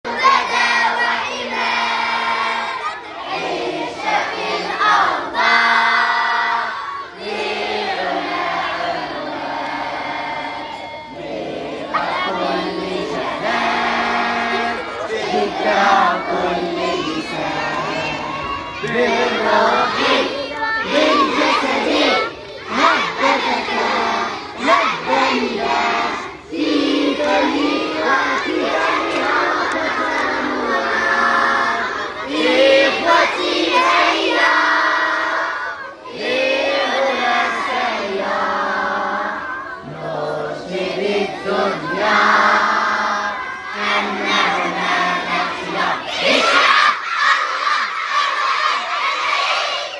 Les élèves participent à des chants, dont l’hymne national. Les CM2 ont découvert ce bel instrument qu’est le sitar et l’ont accompagné pour offrir une chorale aux autres élèves.